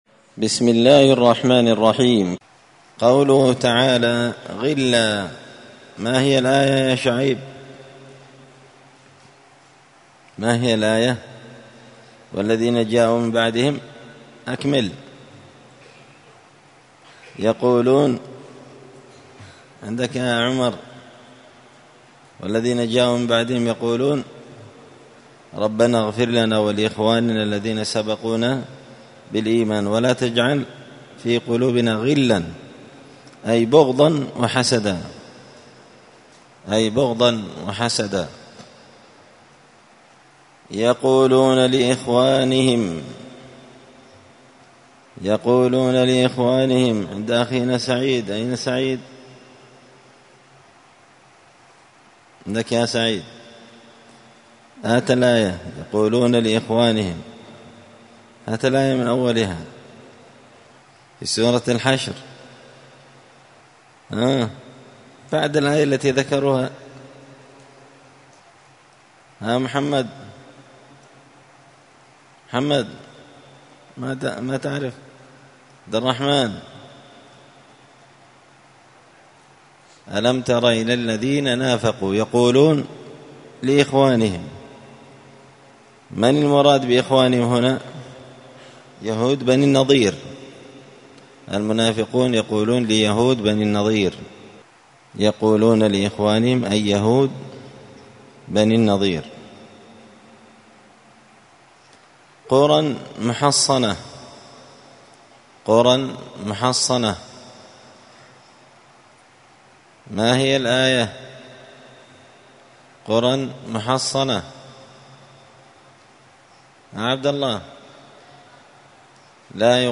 مسجد الفرقان قشن_المهرة_اليمن 📌الدروس اليومية